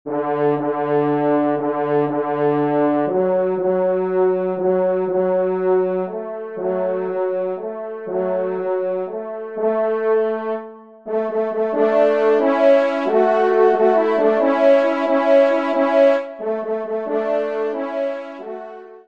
Pupitre 3°Trompe